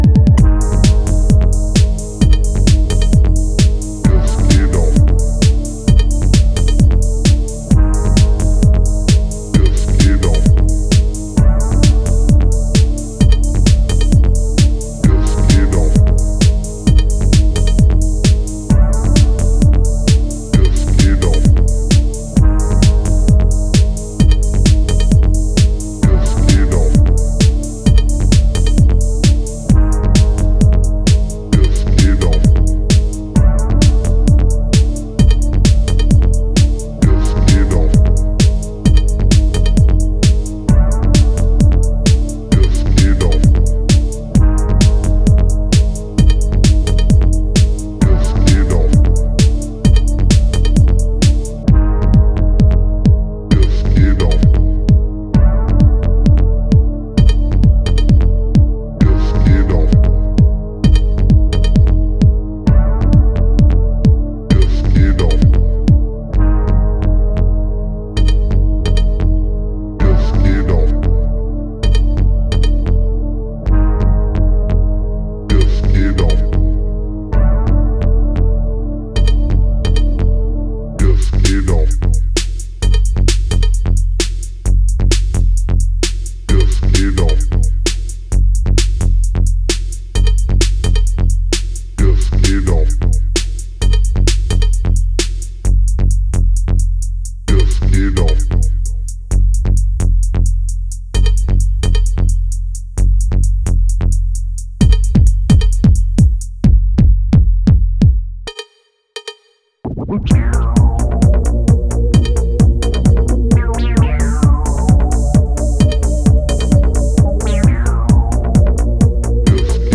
スクリュードヴォイスと沈鬱なストリングスがフロアの闇を色濃くさせる
EBM感覚のダークネスが息づくテック・ハウスを展開